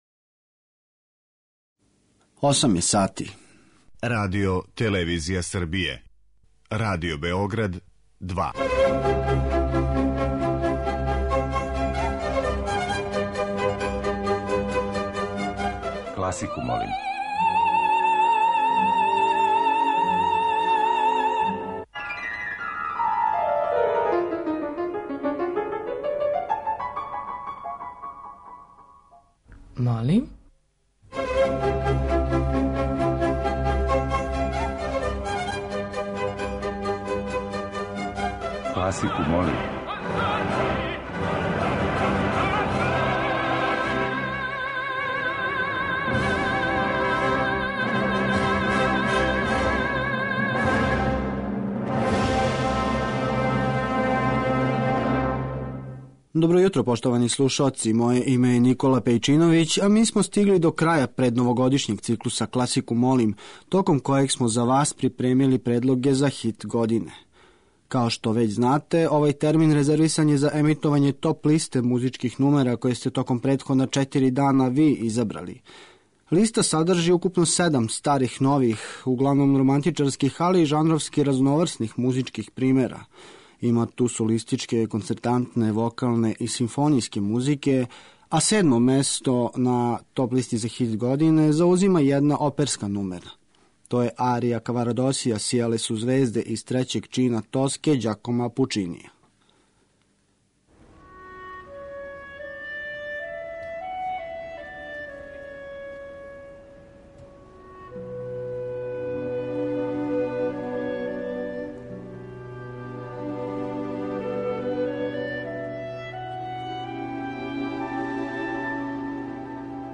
Годишња топ-листа класичне музике Радио Београда 2